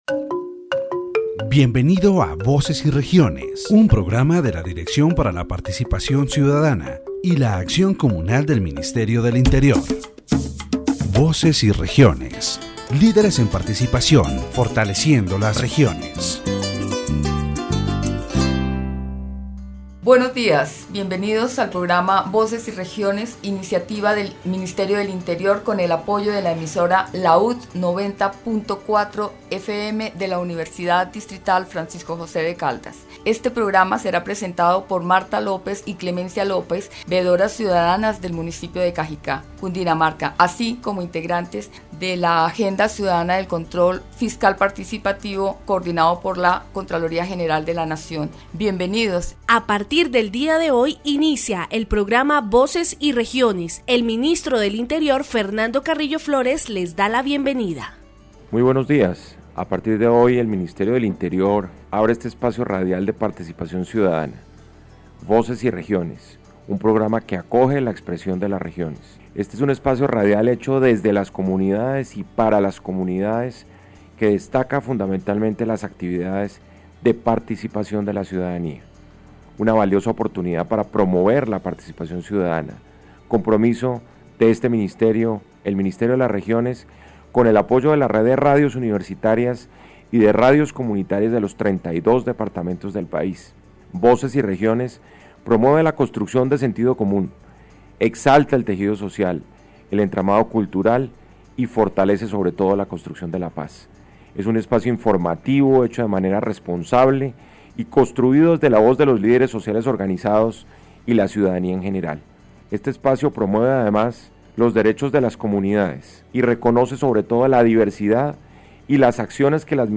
The radio program "Voices and Regions" of the Directorate for Citizen Participation and Communal Action of the Ministry of the Interior focuses on citizen participation and participatory fiscal control in the municipality of Cundinamarca. The program highlights the importance of community participation in solid waste management and the implementation of public policies.